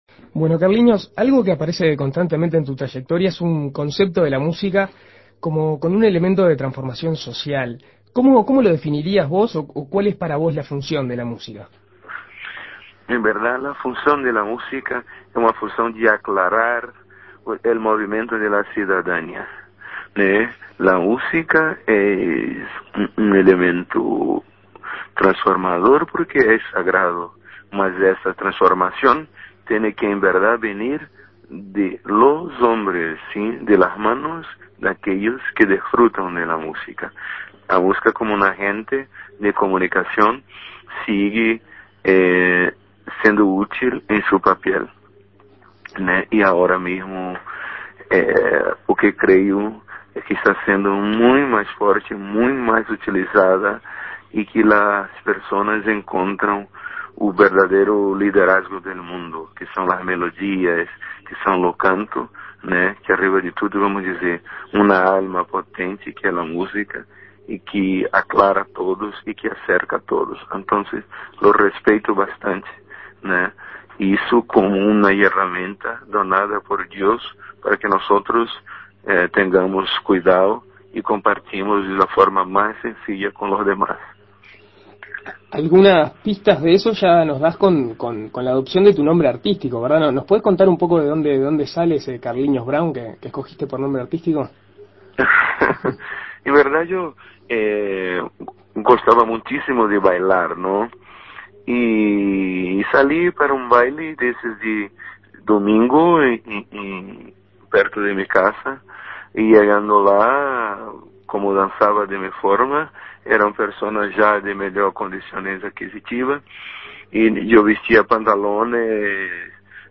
En Perspectiva Segunda Mañana dialogó con este particular artista.